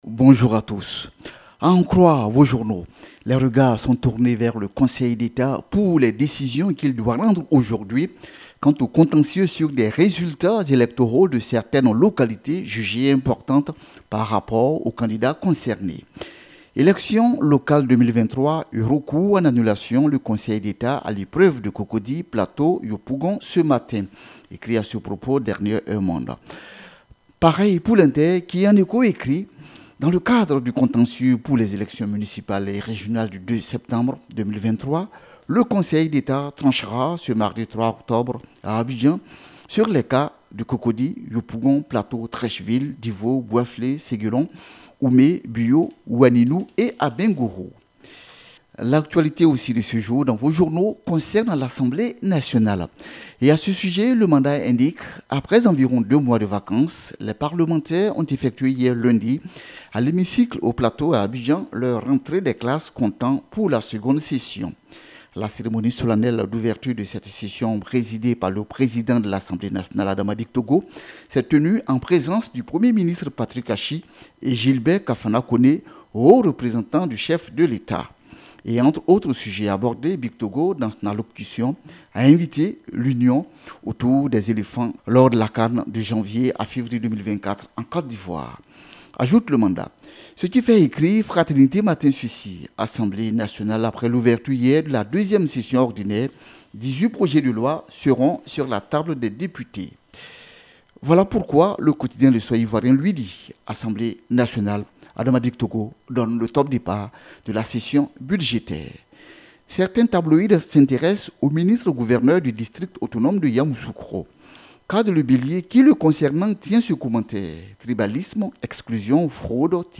Revue de presse du 3 octobre 2023 - Site Officiel de Radio de la Paix